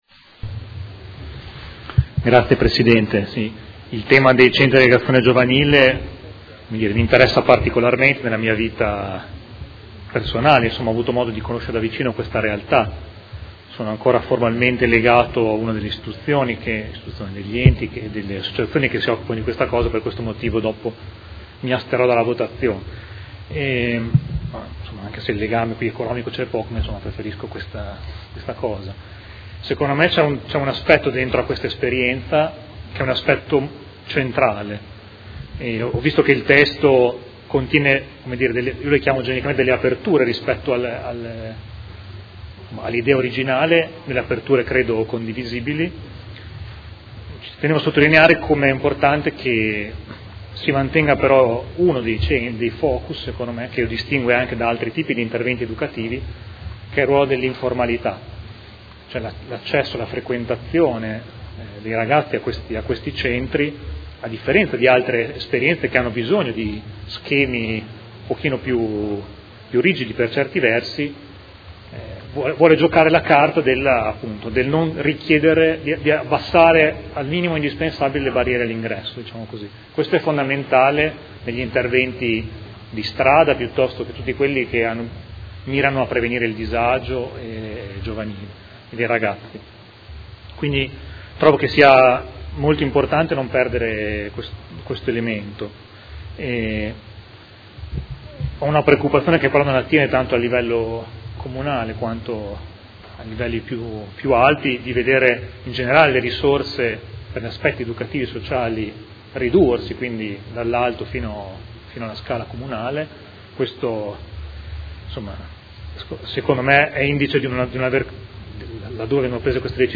Mario Bussetti — Sito Audio Consiglio Comunale